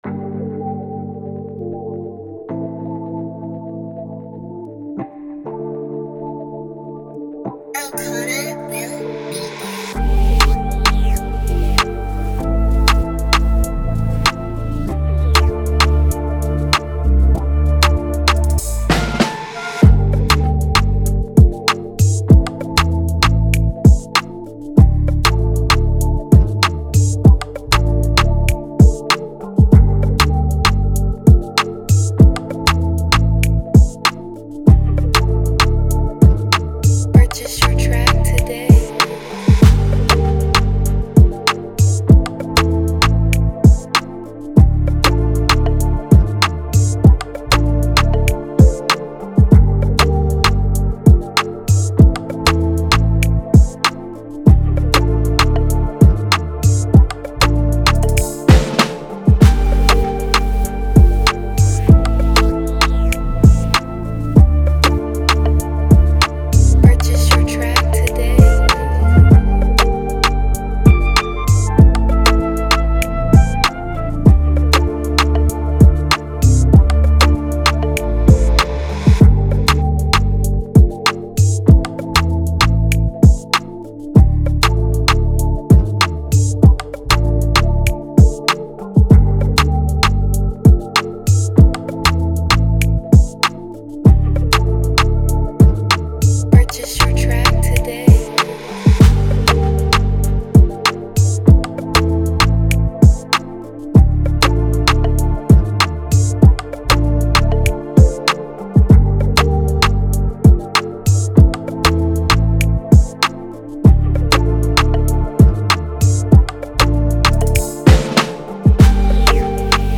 With a tempo of 97 BPM